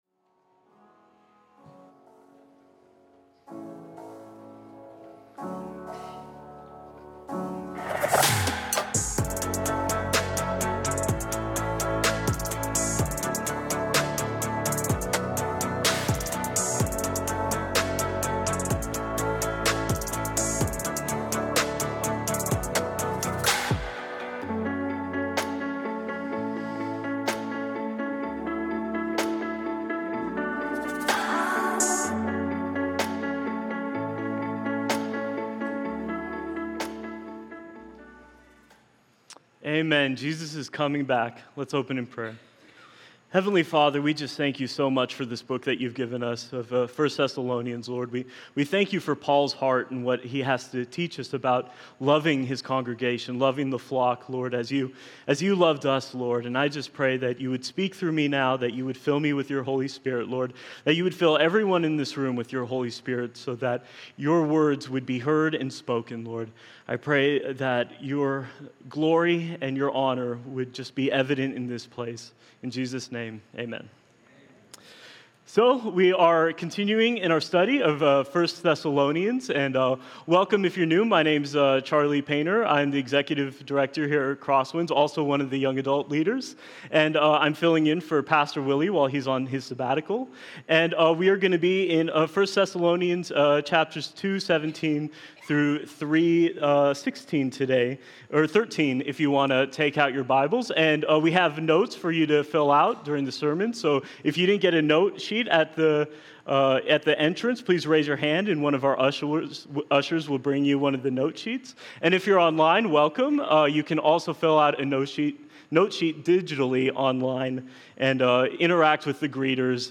Executive Director Sermon Notes Paul and his team had a genuine desire to visit the Thessalonians so…